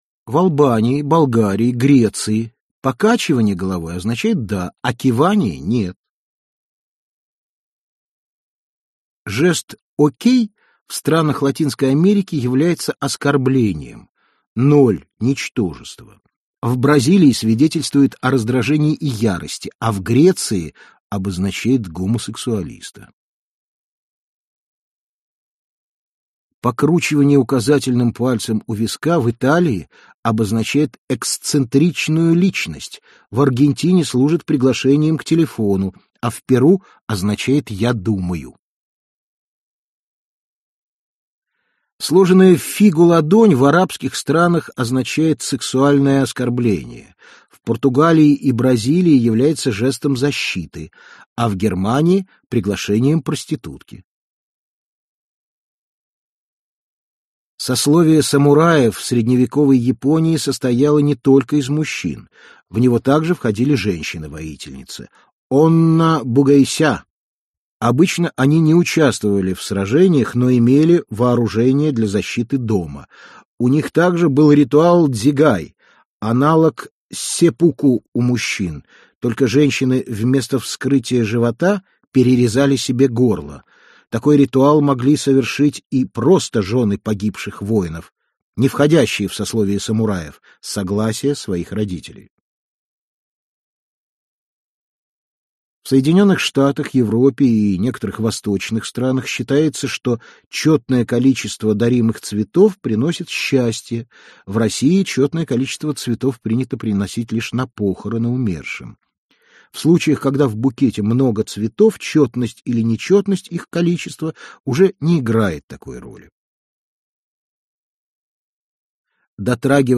Аудиокнига 1500 интересных фактов, которые поражают воображение. Выпуск 2 | Библиотека аудиокниг